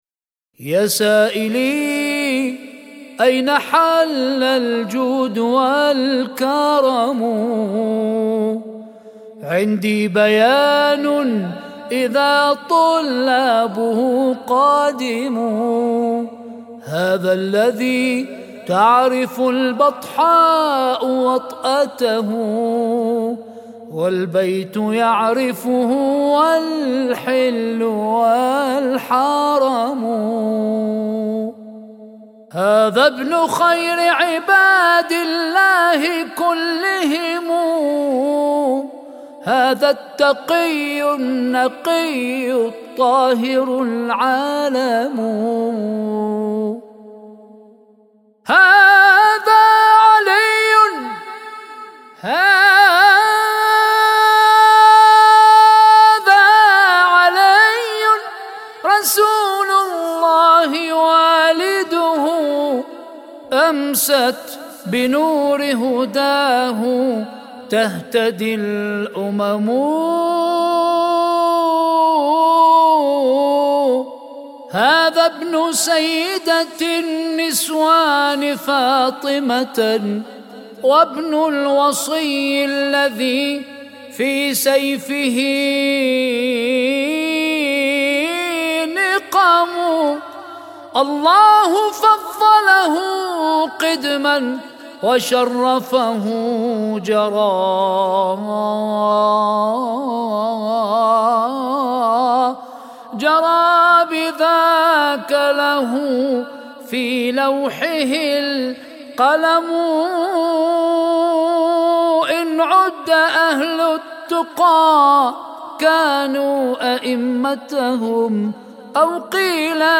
مدائح بمناسبة ذكرى ولادة الإمام السجاد (ع)